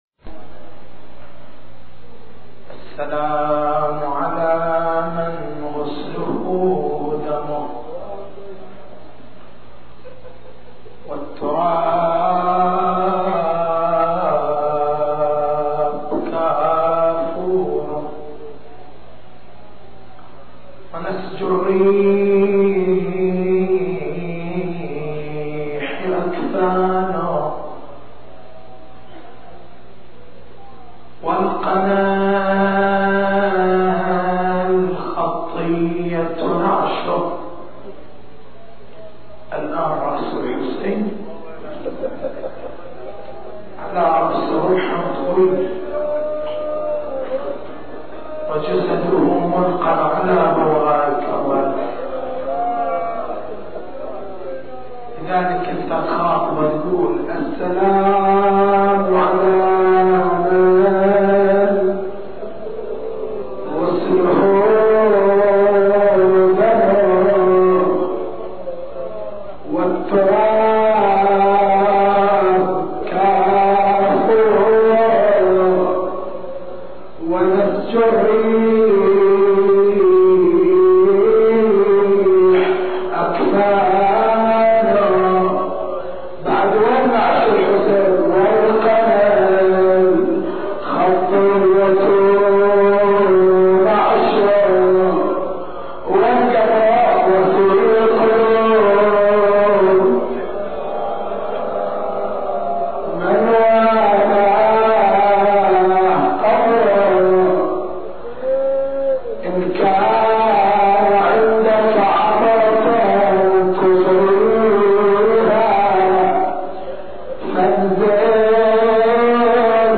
تاريخ المحاضرة: 11/01/1425 نقاط البحث: هل ينفي القرآن إمكان أن تبكي السماء على أحد؟